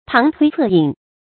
旁推側引 注音： ㄆㄤˊ ㄊㄨㄟ ㄘㄜˋ ㄧㄣˇ 讀音讀法： 意思解釋： 從側面啟發引導。